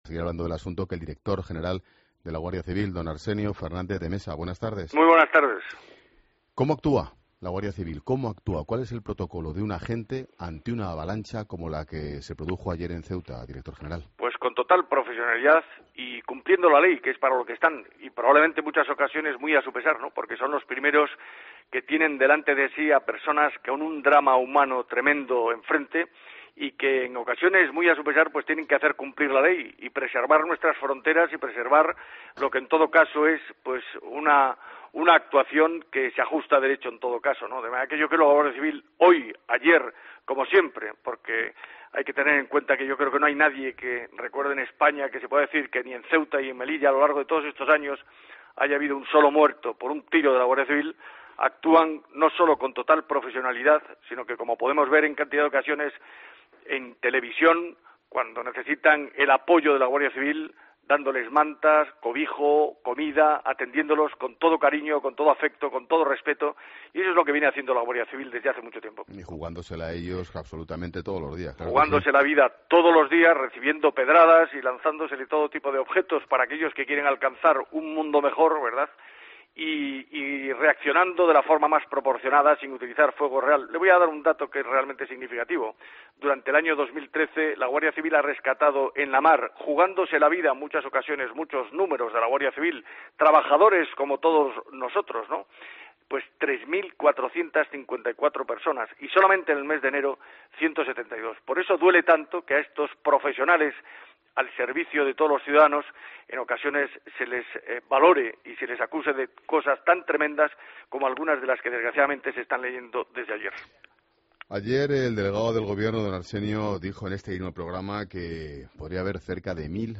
AUDIO: Escucha la entrevista completa a Arsenio Fernández de Mesa en 'Mediodía COPE'